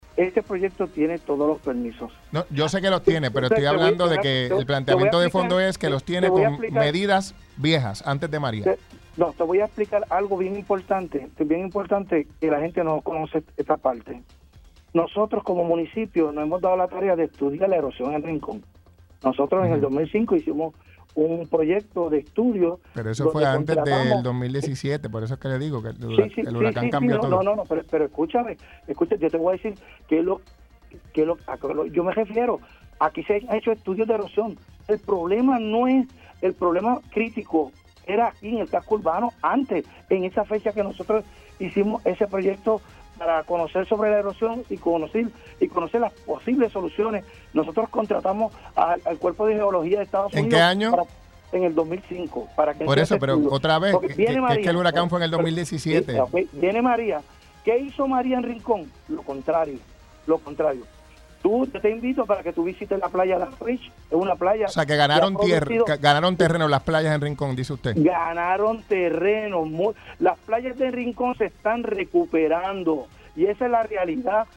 Yo entiendo que la protesta no está justificada porque este proyecto cuenta con todos los endosos y permisos del Gobierno de Puerto Rico y el Gobierno federal. Y como te digo, esto es un proyecto que es público y es para la gente. Y tú escuchas a la gente hablando de que la playa es del pueblo, pero la playa es de los surfers, la playa es de todos los individuos, la playa es de los ancianitos que puedan bajar en sillón de ruedas. Por este proyecto van a poder bajar sin problema y disfrutar de una playa que las personas con impedimentos ahora no pueden“, aseguró en entrevista para Pega’os en la Mañana.